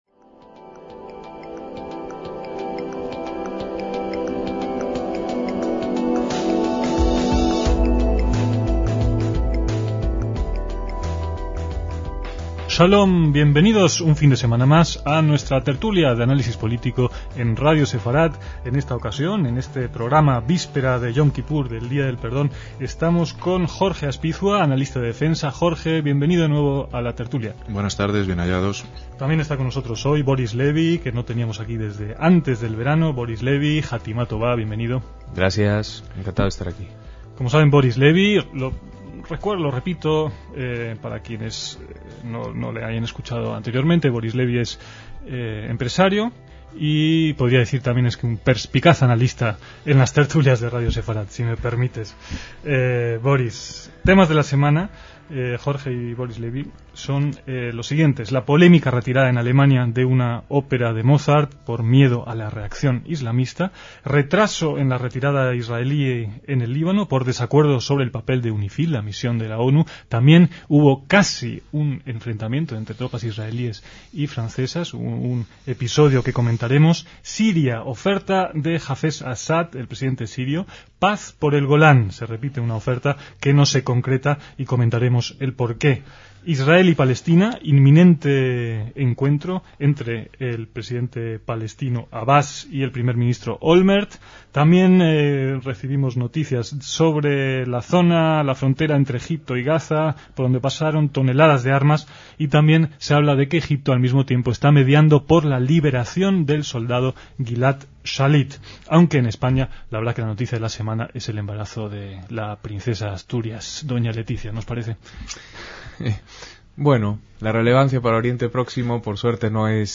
En la tertulia tras el inicio del año nuevo judío en 2006